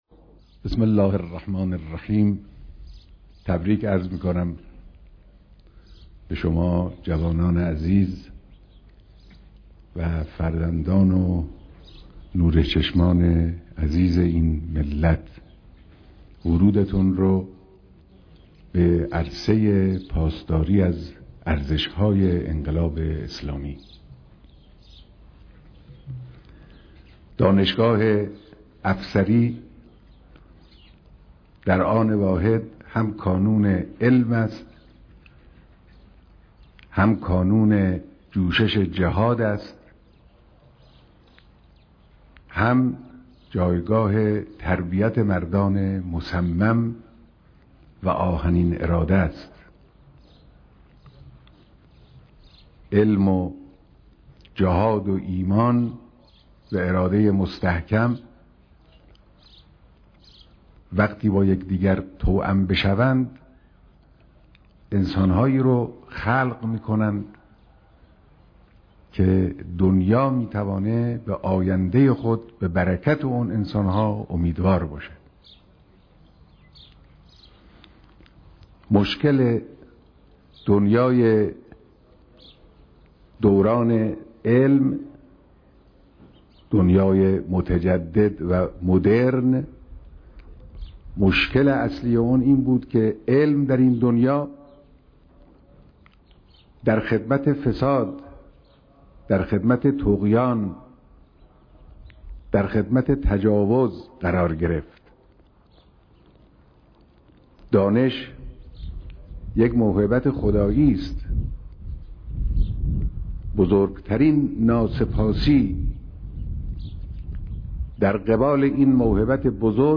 مراسم مشترك دانش‌آموختگيِ دانشجويان دانشگاه افسري امام حسين(ع)